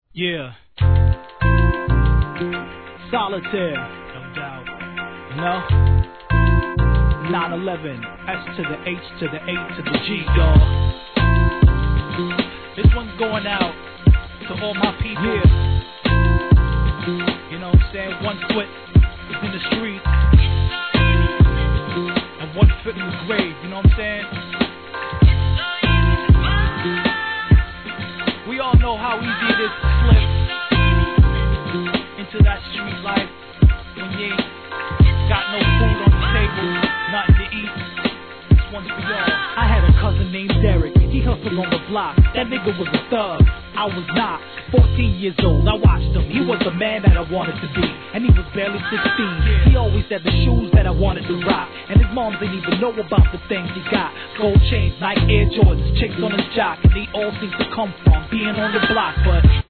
HIP HOP/R&B
心地よいメローなとらっくに悲しげな女性ヴォーカルのLOOPが肝となった素通りするのは惜しいマイナー盤HIP HOP!!